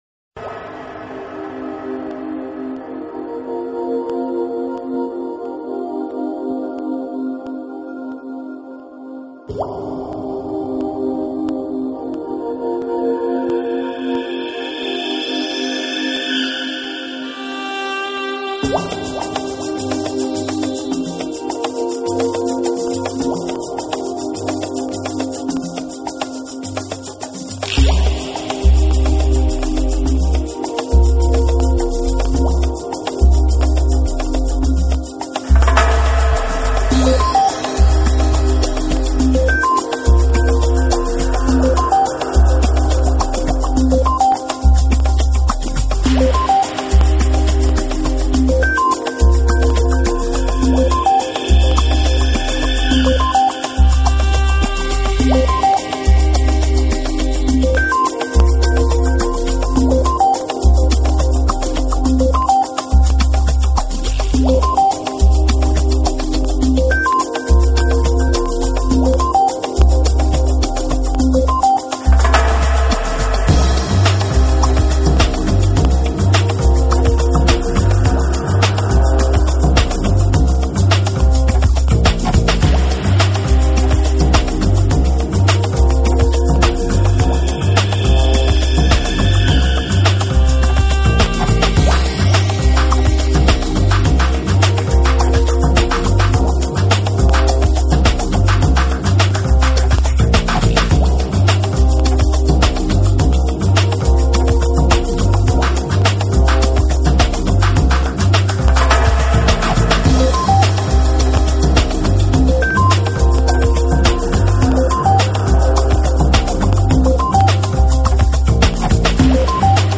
Style: Ambient, Lounge, Chillout
Quality: VBRkbps / 44.1kHz / Joint-Stereo